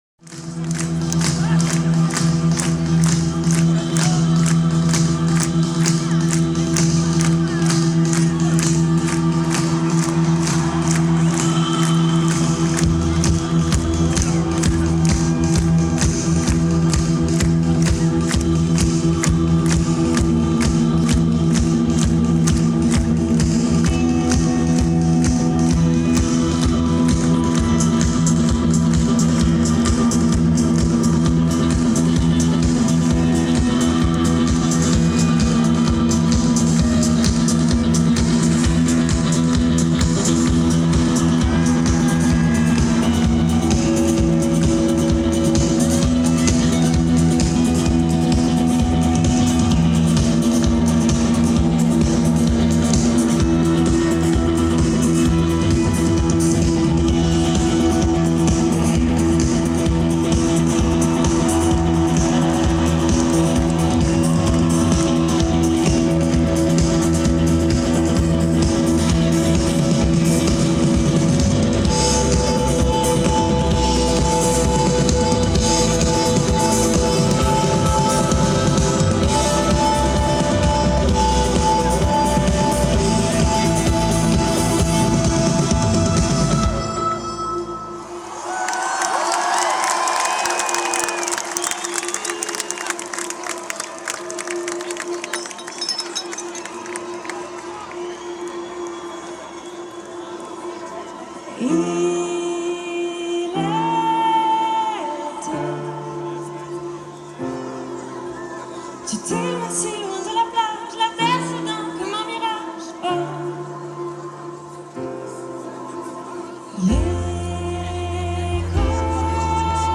Hotel de Ville de Paris